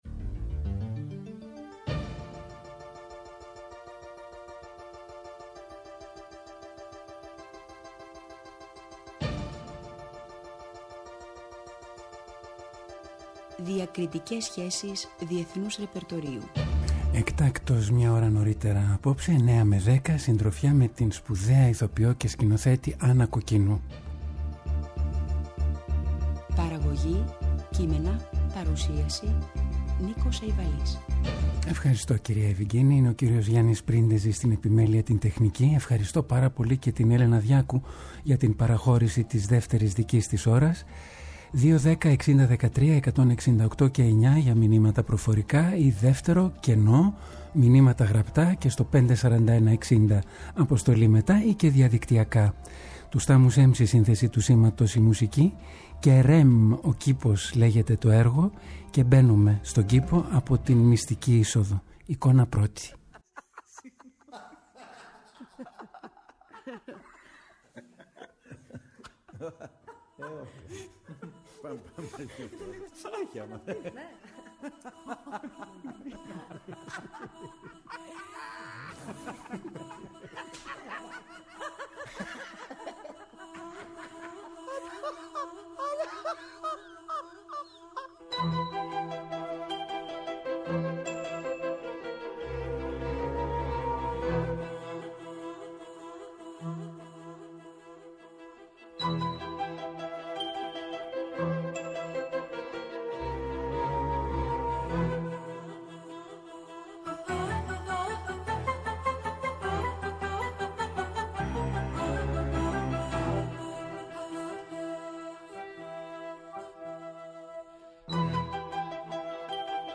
Η Άννα Κοκκίνου στο Μονόπρακτο της Παρασκευής. Η σπουδαία Ηθοποιός και Σκηνοθέτης μίλησε για το Θέατρο με αφορμή την ονειρική παράσταση του έργου “REM/ κήπος”, που σκηνοθετεί και πρωταγωνιστεί στο Θέατρο Σφενδόνη.
ΔΕΥΤΕΡΟ ΠΡΟΓΡΑΜΜΑ Συνεντεύξεις